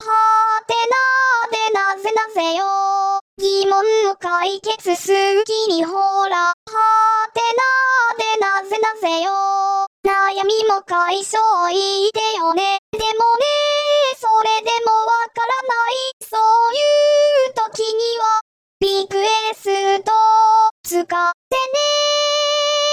投稿してもらった歌詞は、UTAUを用いて重音テトさんに歌ってもらい、そのデータを質問文中のアドレス（はてなグループのページ）にアップします。
・こだわると大変なのでUTAわせるときは、「おま☆かせ」くらいの調声しかしません。